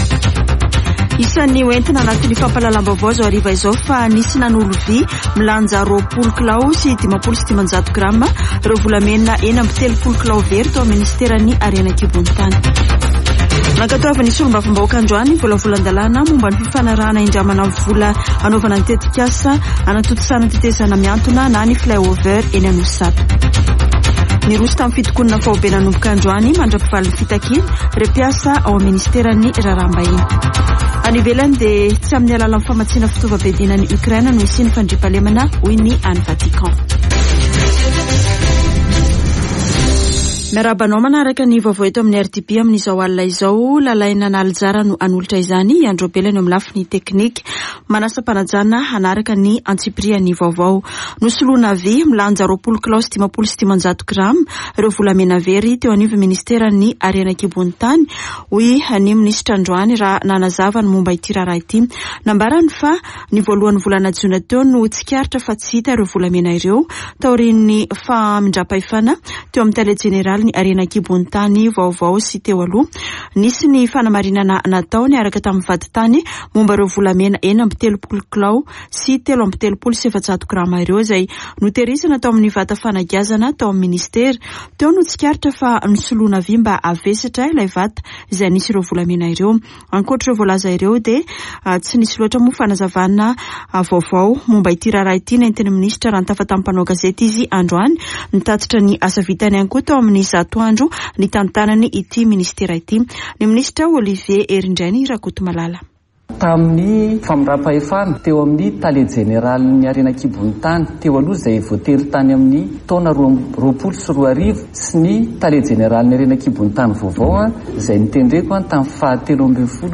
[Vaovao hariva] Zoma 24 jona 2022